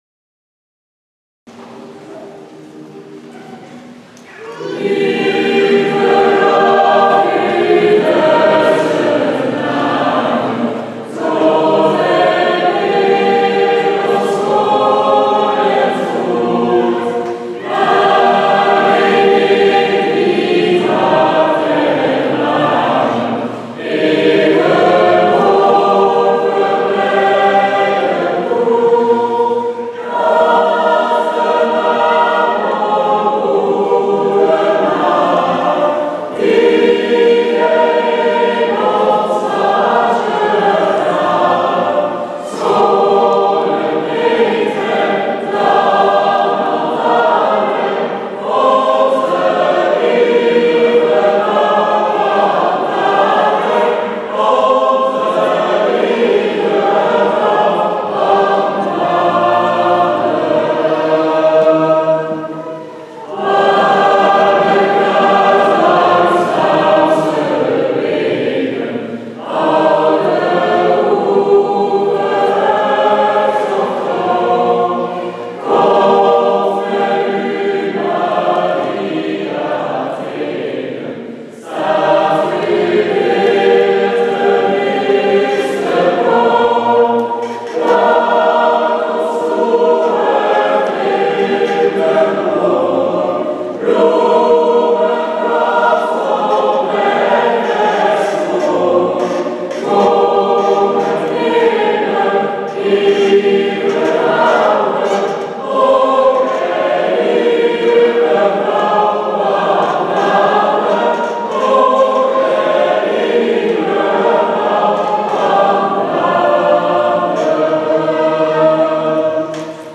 uitgevoerd gelegenheidskoor op 27 oktober 2014 Gemenos (FR).